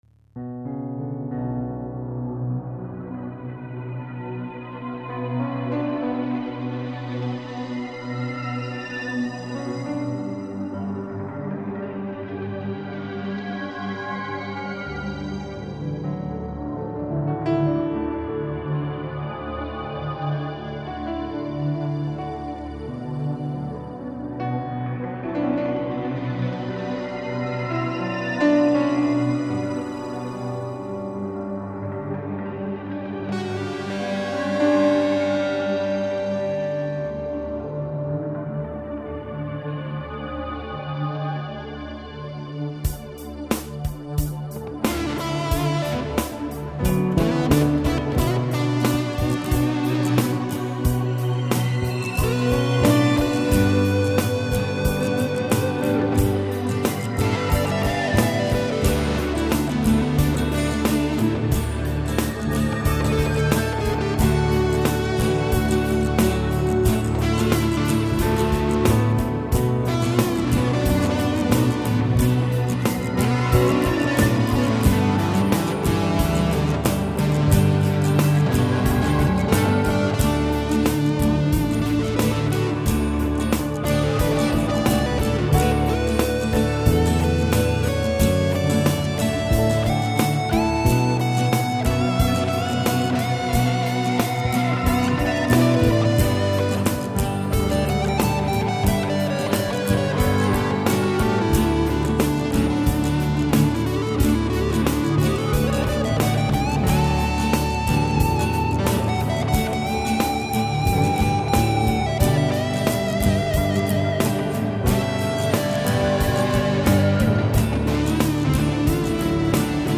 Epic D major work out!